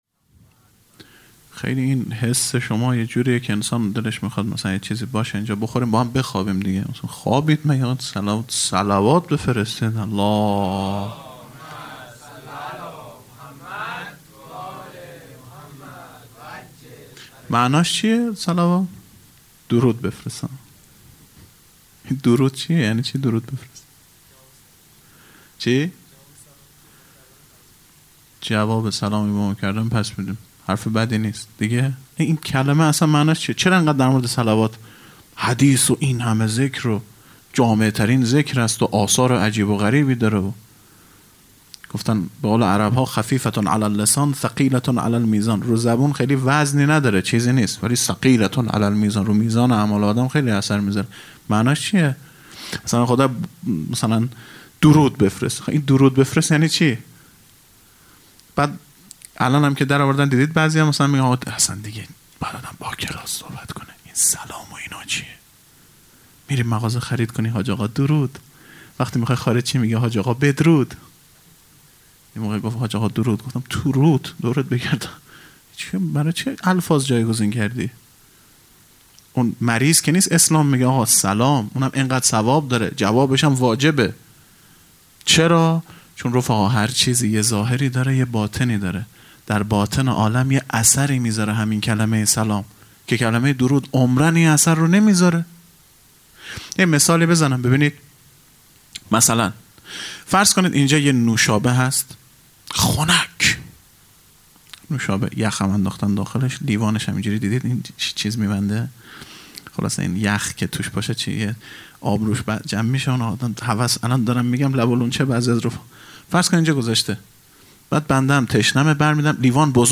خیمه گاه - هیئت بچه های فاطمه (س) - سخنرانی | ۲۹ اردیبهشت 1401